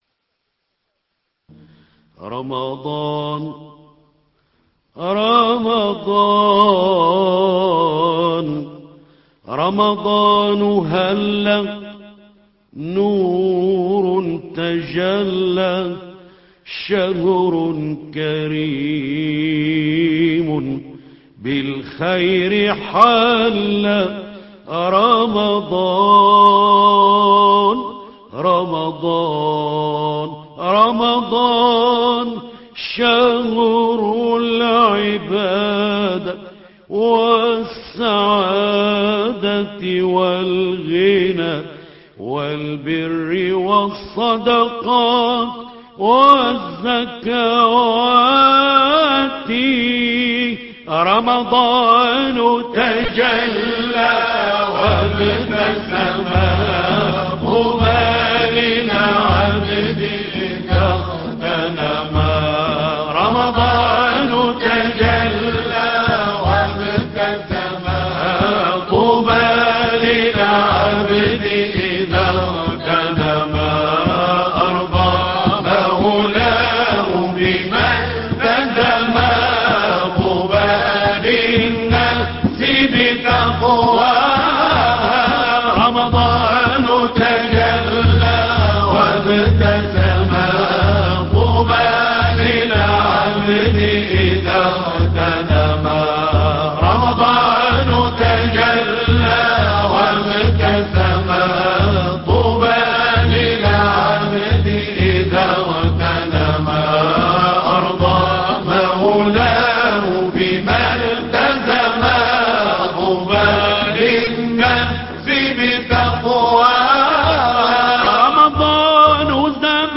نغمة العجم